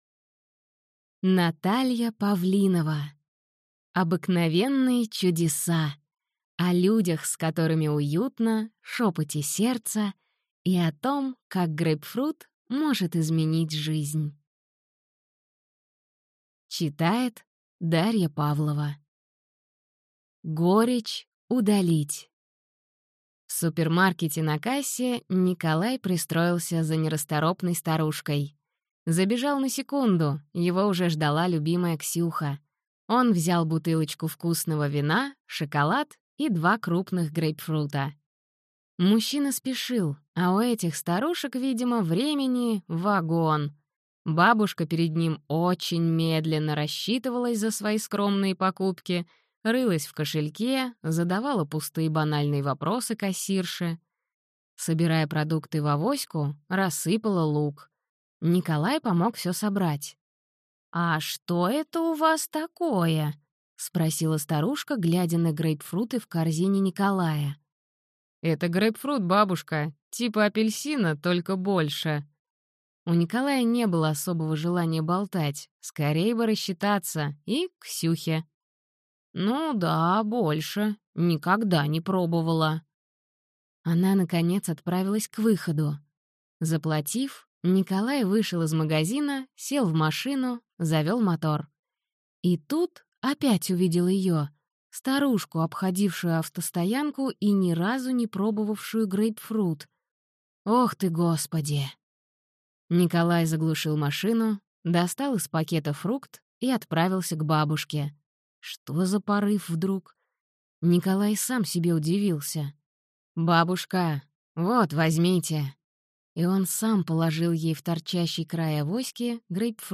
Аудиокнига Обыкновенные чудеса. О людях, с которыми уютно, шепоте сердца и о том, как грейпфрут может изменить жизнь | Библиотека аудиокниг